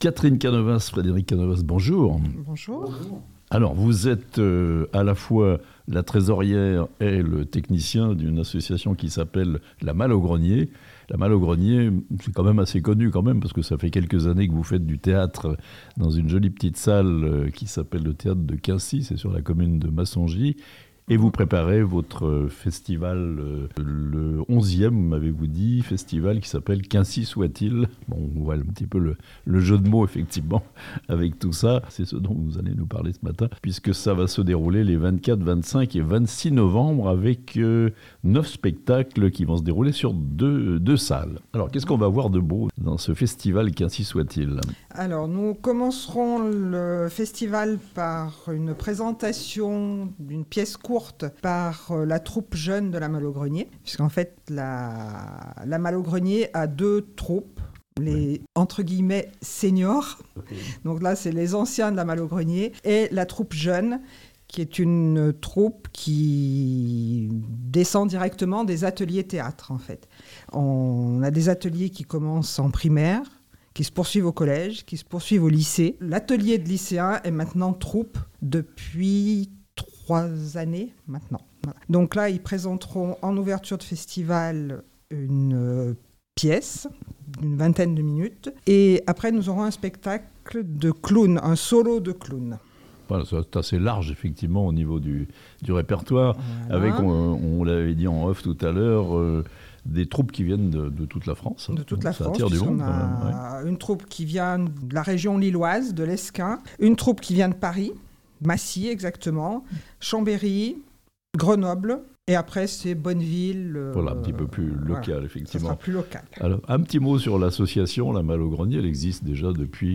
Massongy : l'association "La Malle au Grenier" présente son 11ème festival de théâtre (interviews)